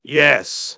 synthetic-wakewords
ovos-tts-plugin-deepponies_Luna_en.wav